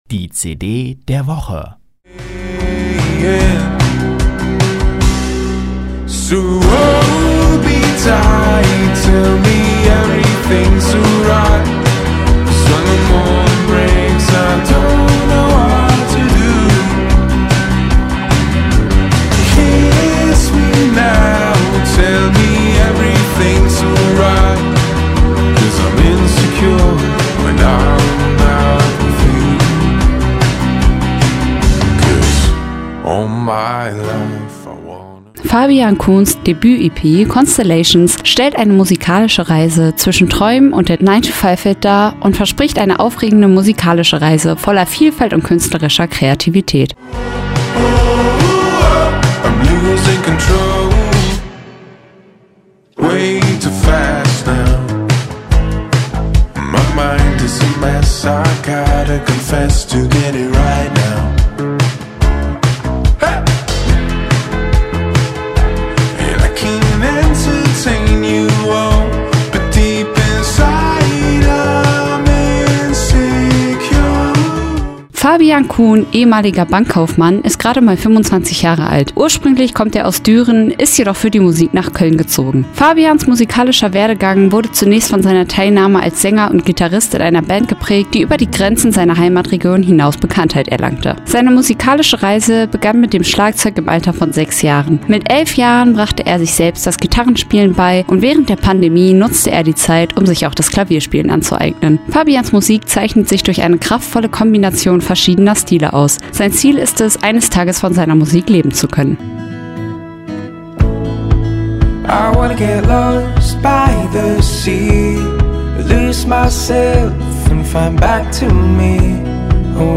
welcher die Songs am Schlagzeug begleitete.